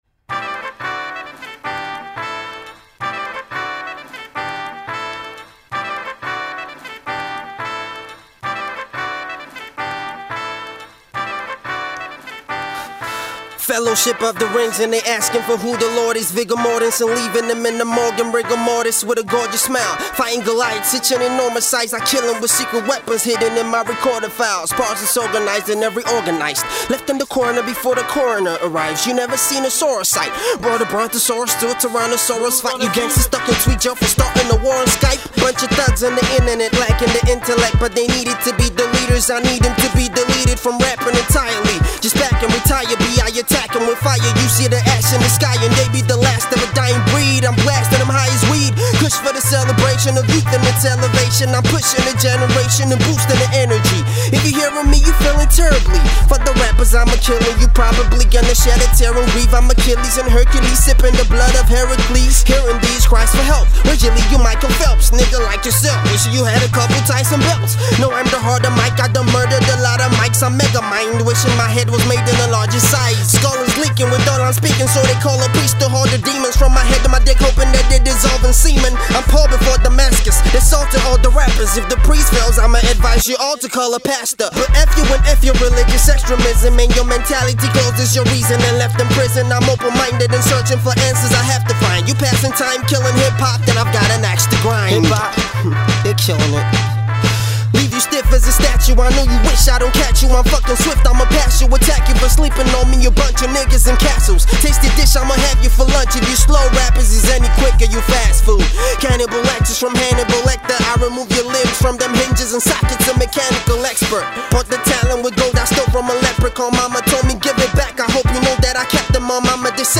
Jazzy Hip-Hop instrumentation